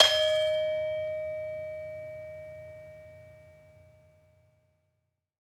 Saron-1-D#4-f.wav